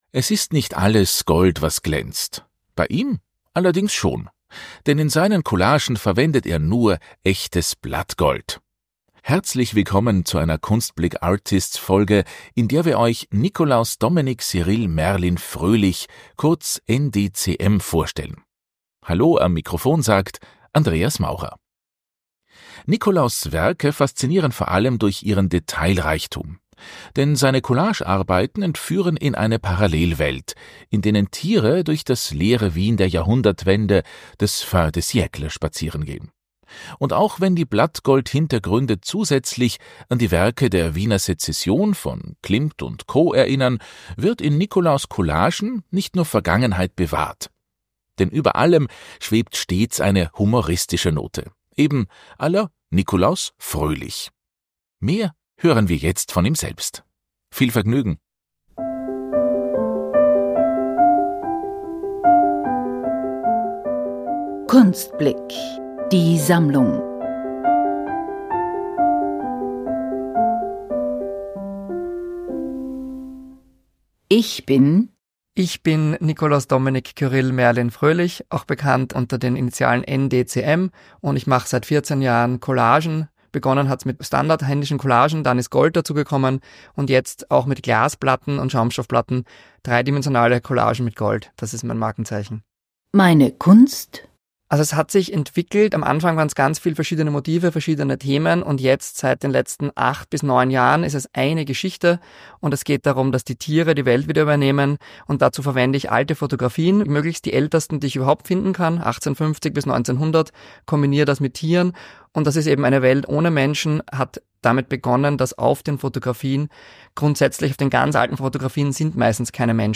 Mehr hören wir jetzt von ihm selbst.